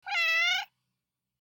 Fiji Meow Sound Effect Download: Instant Soundboard Button